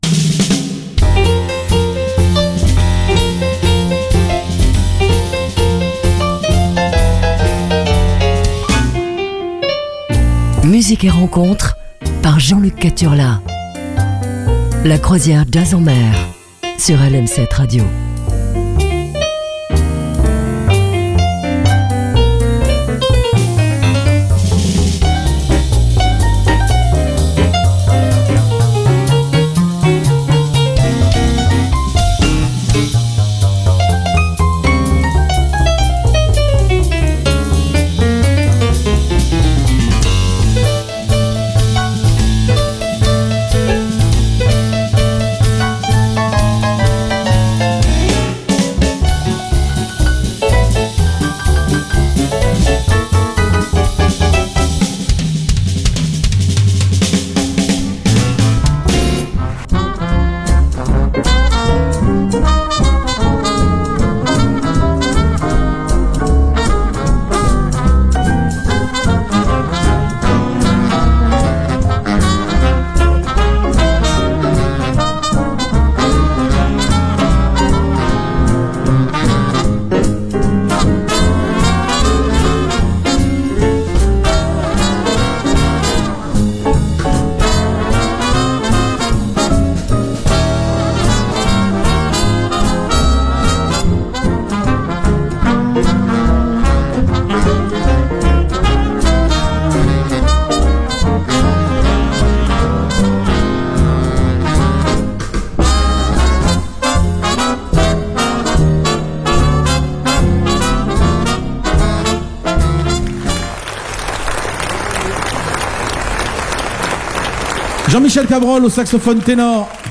passer une demi-heure sur des rythmes jazzy